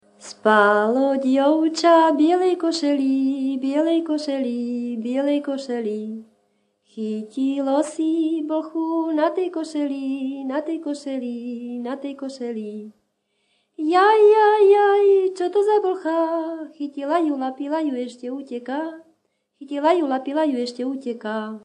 Descripton sólo ženský spev bez hudobného sprievodu
Place of capture Litava
Subject Classification 11.7. Piesne pri tanci
Key words ľudová pieseň